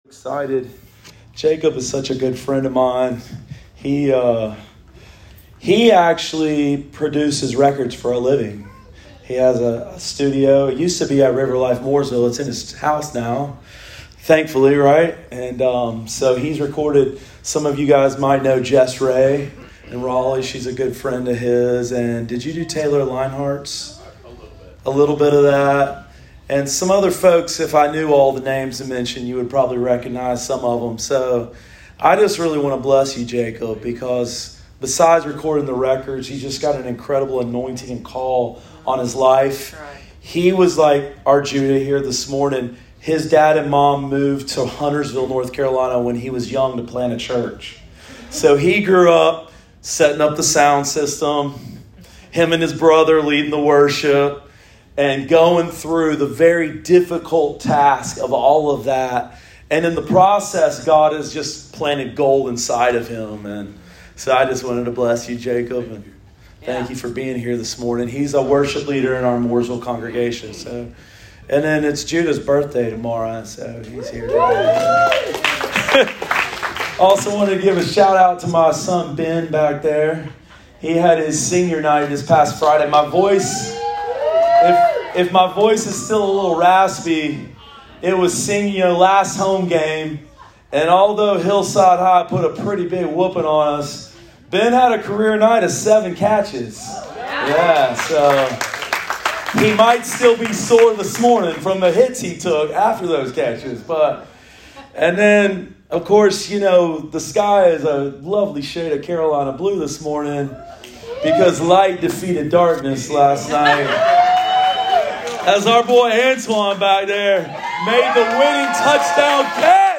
Sermon of the Week: 10/16/22 – RiverLife Fellowship Church